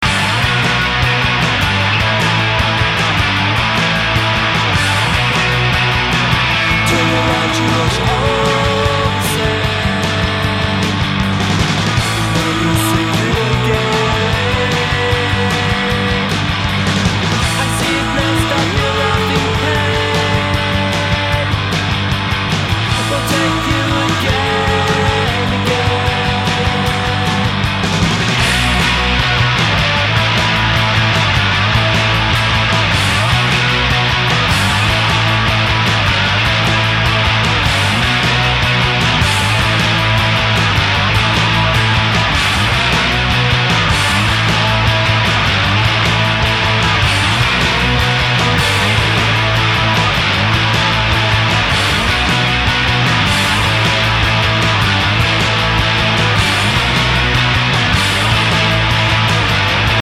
quanto mais tempo passa e se pensa em shoegaze